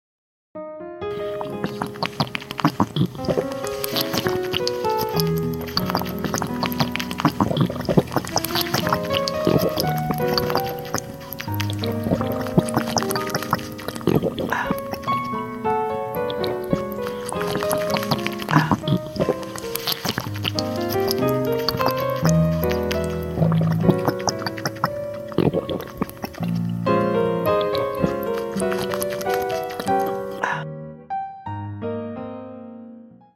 Asmr Mukbang Animation
Asmr Drinking Sound